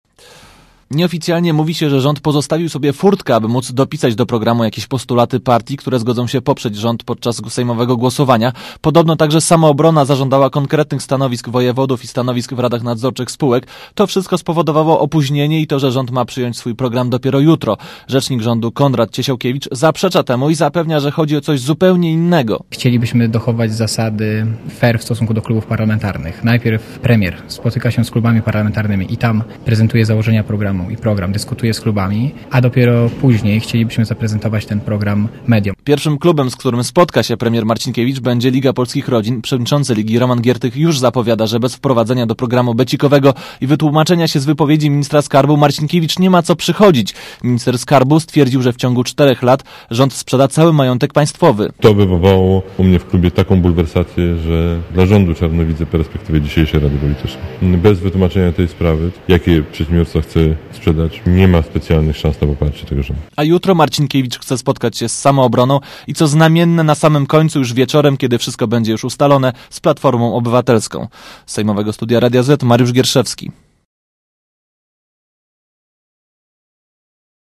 Źródło zdjęć: © PAP 08.11.2005 | aktual.: 08.11.2005 14:47 ZAPISZ UDOSTĘPNIJ SKOMENTUJ Relacja reportera Radia ZET